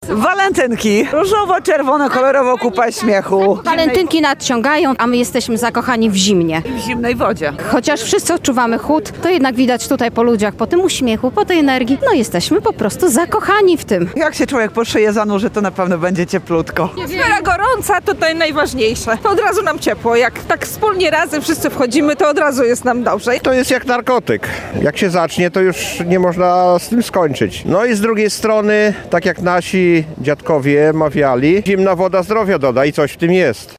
Z miłości do zimna – na walentynkowym morsowaniu spotkali się lubelscy entuzjaści lodowatych kąpieli. W samo południe przy tamie nad Zalewem Zemborzyckim zgromadziło się kilkadziesiąt osób, które zimny wiatr pokonały zanurzając się po szyję w zimnej wodzie.